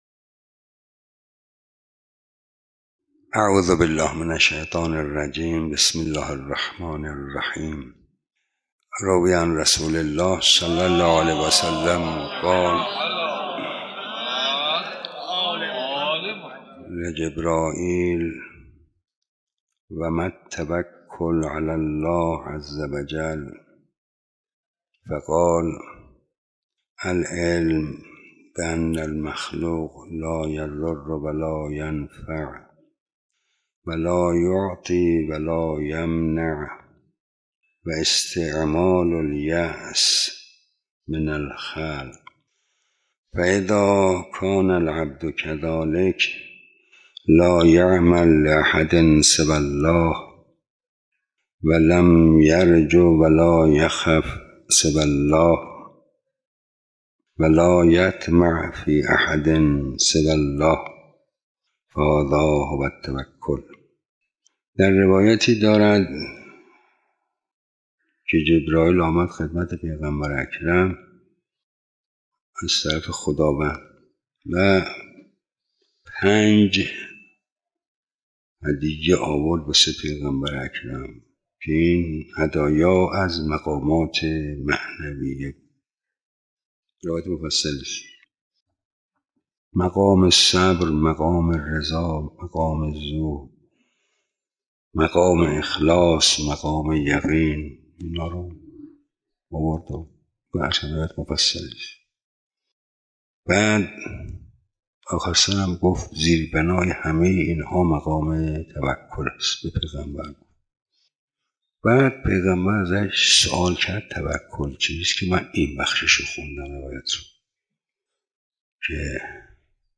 عقیق: مرحوم آیت الله آمجتبی تهرانی در یکی از سخنرانی های خود به موضوع «توکل در نگاه توحیدی» پرداختند که تقدیم شما فرهیختگان می شود.
حاج آقا مجتبی تهرانی توکل درس اخلاق